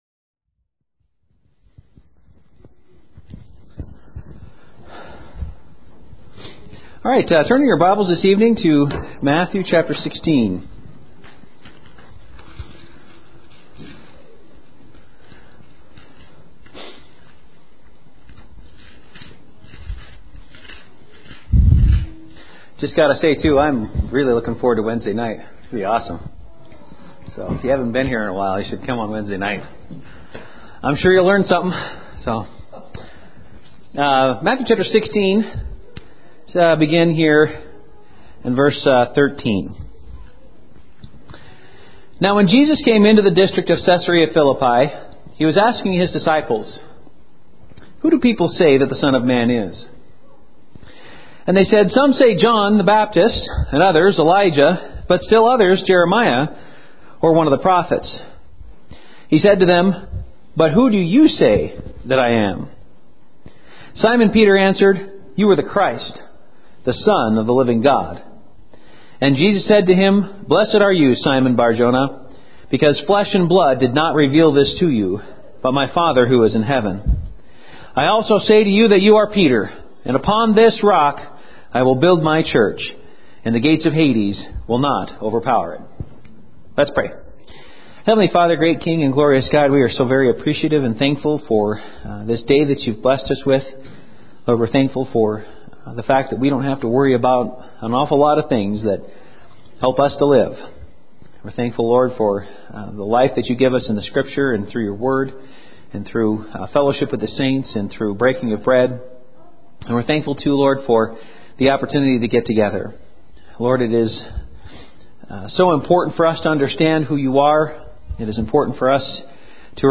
Series: Evening Messages